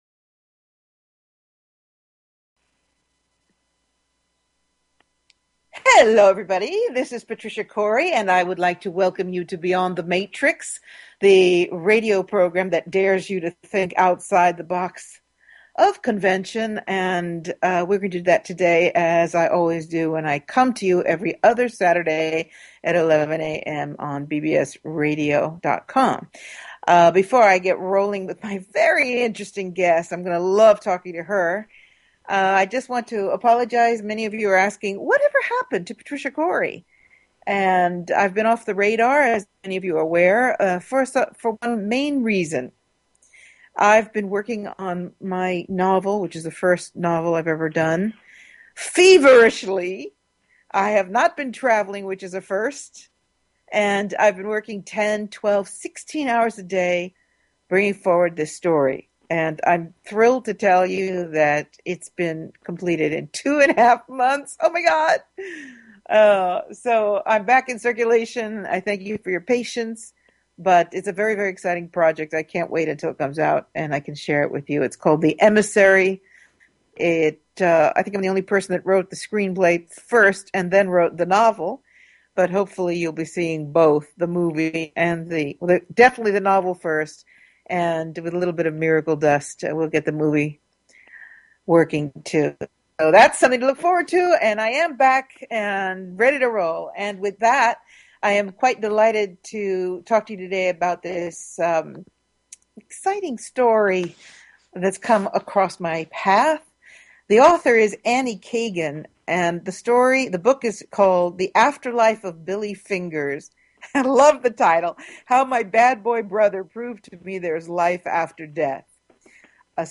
Talk Show Episode, Audio Podcast, Beyond_The_Matrix and Courtesy of BBS Radio on , show guests , about , categorized as